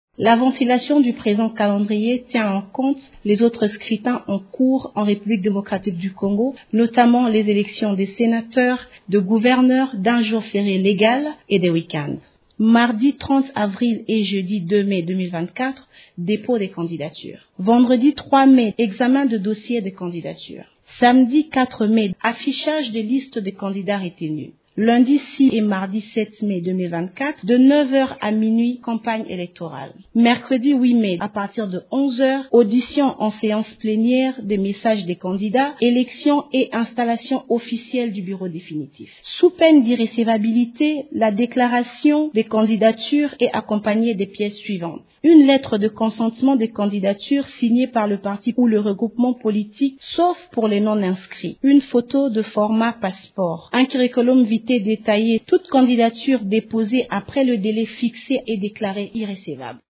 Vous pouvez écouter Percy Nzazi Umba dans cet extrait :